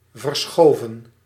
Ääntäminen
IPA: /vərˈsxoːvə(n)/